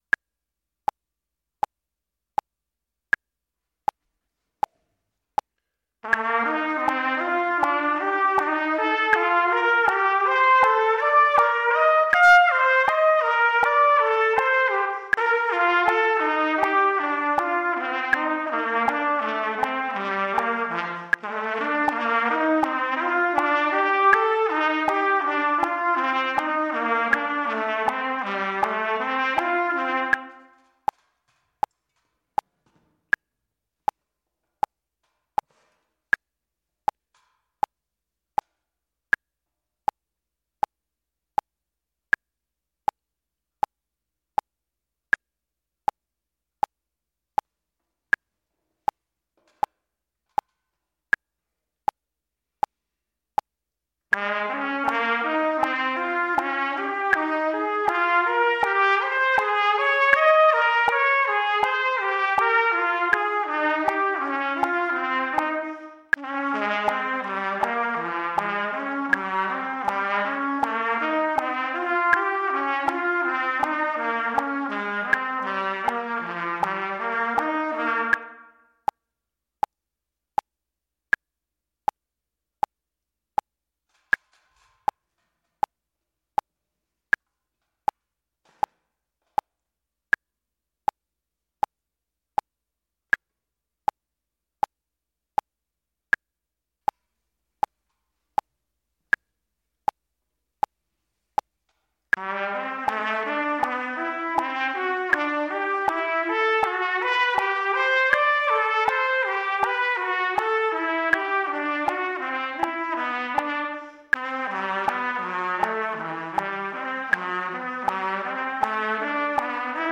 Interval-of-a-Fourth-Flow-Study-80-Final.mp3